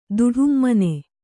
♪ duḍhummane